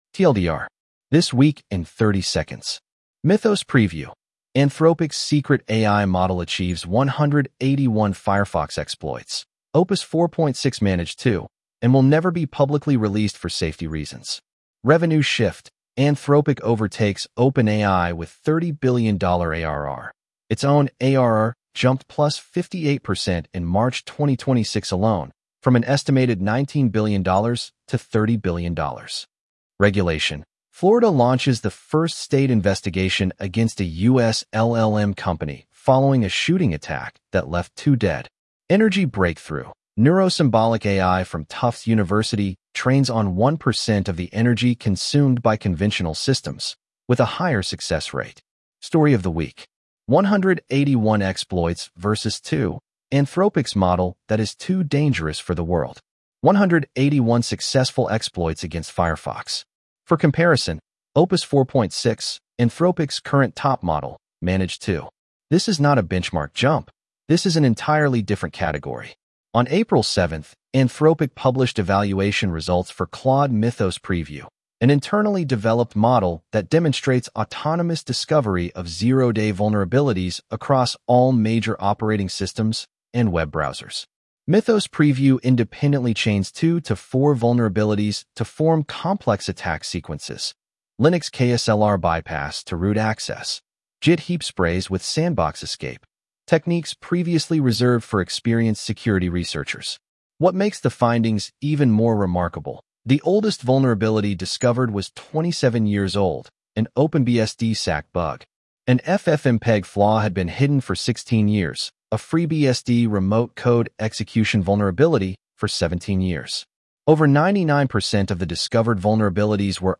Read aloud with edge-tts (en-US-AndrewNeural)